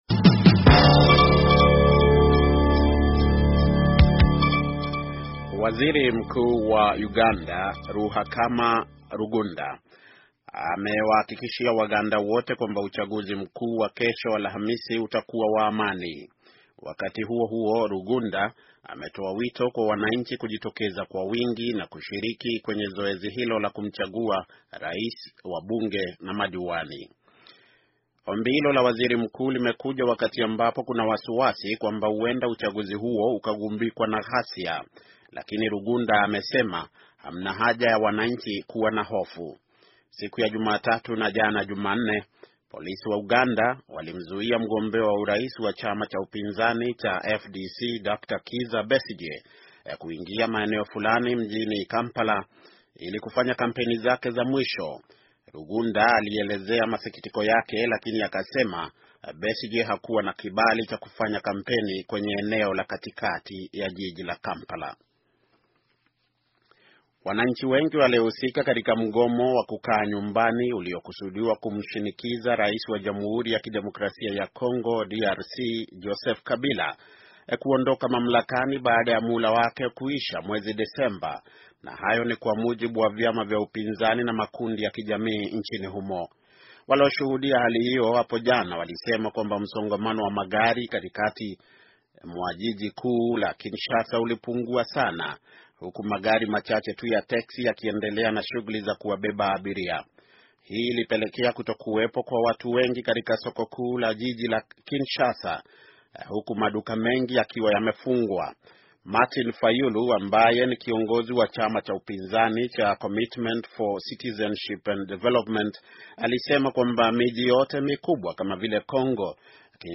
Taarifa ya habari - 7:00